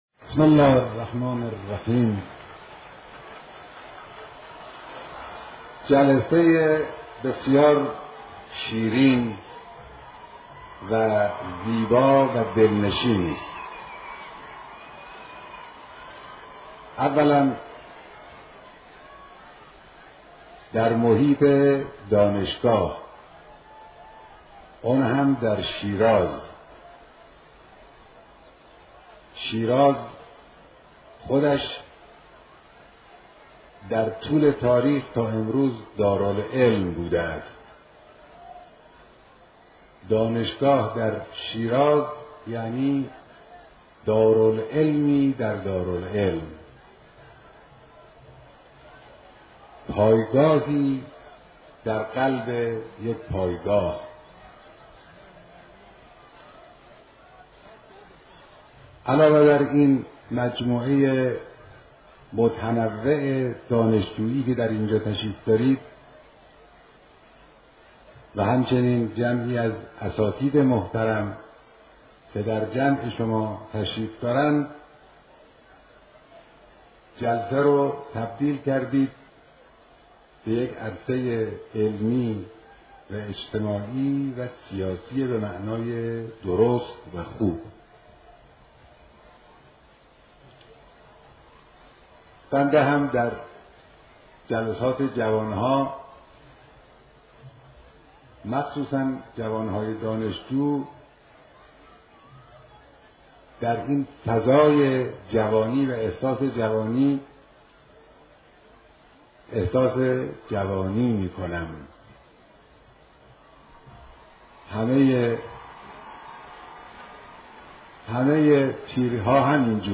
دیدار هزاران نفر از استادان و دانشجویان دانشگاههای استان فارس